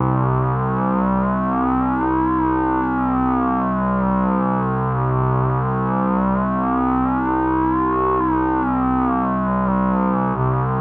LONG SYNC.wav